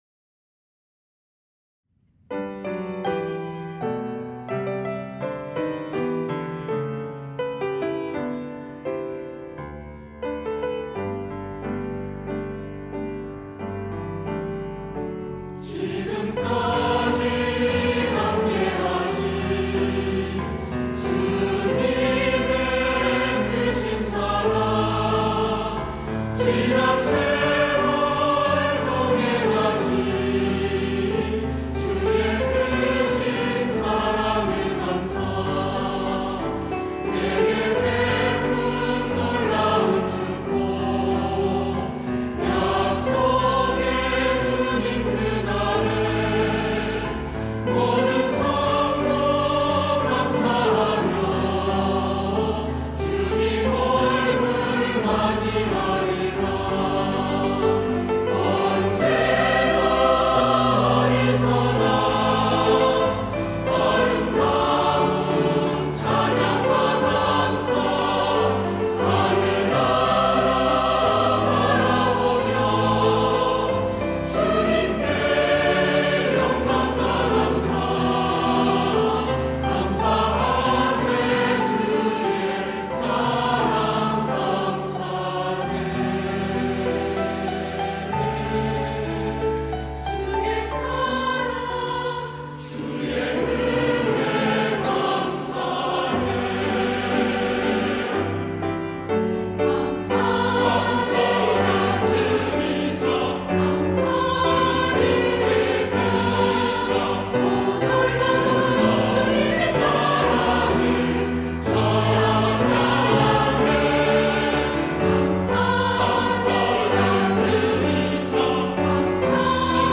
할렐루야(주일2부) - 주의 모든 일에 감사드리며
찬양대